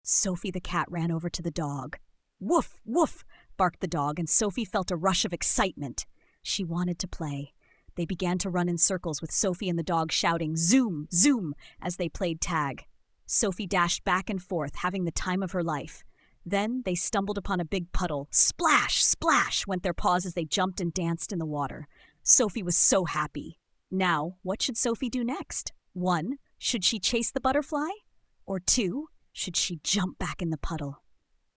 Hour 6 and 7: Prompt Tweaking and Voice Mode
In some ways this was similar to project 1 with it's story generation, but I also added some fresh components like the image generation and text to speech.